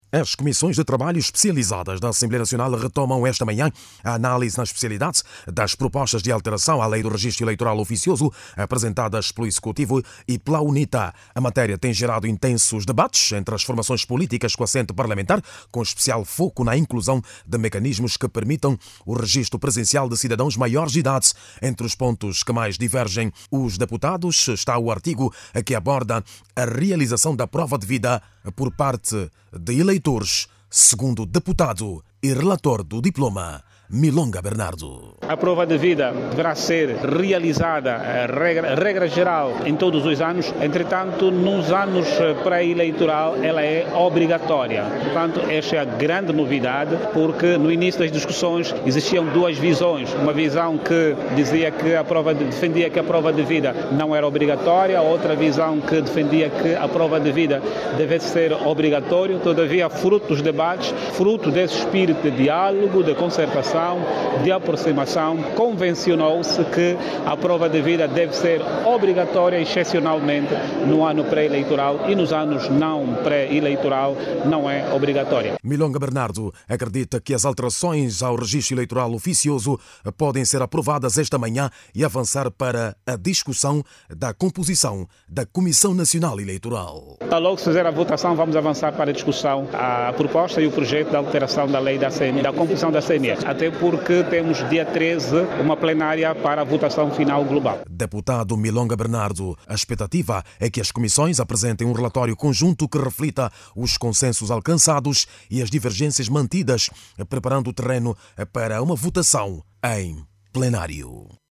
As alterações à Lei do Registo Eleitoral Oficioso, propostas pelo Executivo e pela UNITA, voltam hoje, quarta-feira(06), à mesa das comissões especializadas da Assembleia Nacional, numa fase crucial do debate legislativo. Em destaque está a proposta que prevê o registo presencial de cidadãos maiores de idade, assim como a definição de novos mecanismos para a actualização e verificação dos dados constantes nos cadernos eleitorais. Clique no áudio abaixo e ouça a reportagem